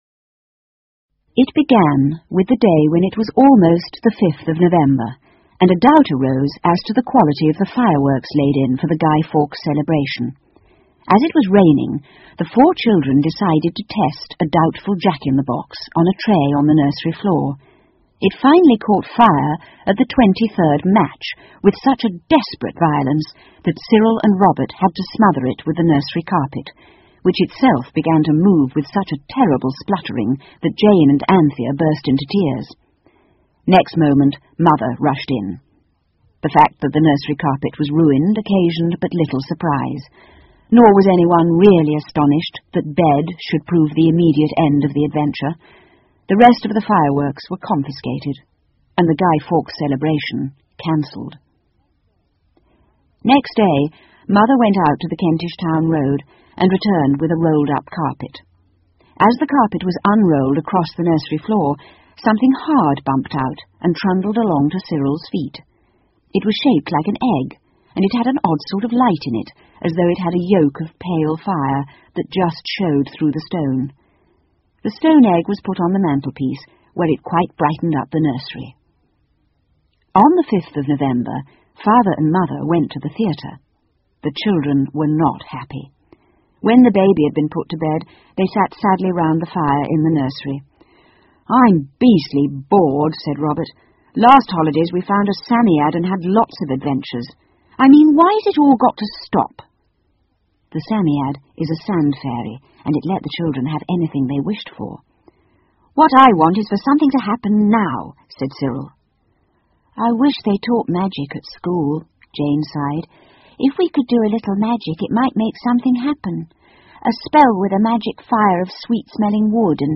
凤凰与魔毯 The Phoenix and the Carpet 儿童英语广播剧 1 听力文件下载—在线英语听力室